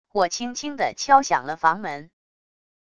我轻轻地敲响了房门wav音频生成系统WAV Audio Player